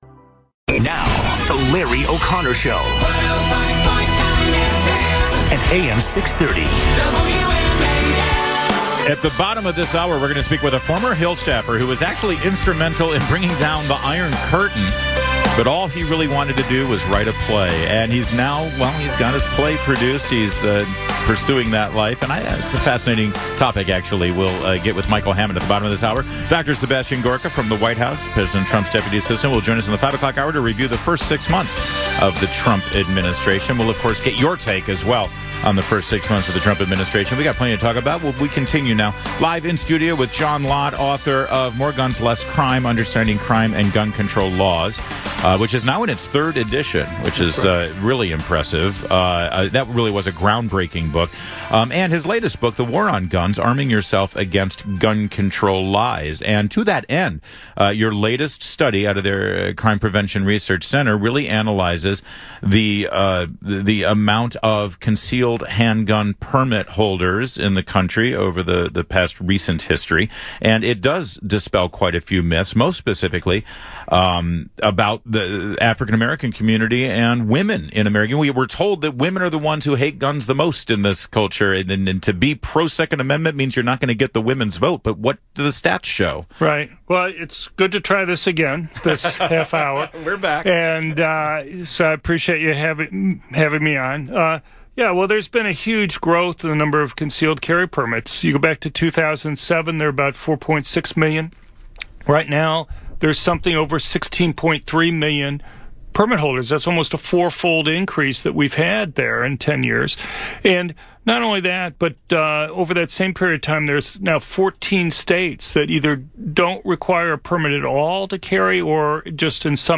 On WMAL's Larry O'Connor Show to talk about the new CPRC report on concealed handgun permits - Crime Prevention Research Center
Dr. John Lott talked to Larry O'Connor on the DC's giant WMAL to discuss the new CPRC report on concealed handgun permits.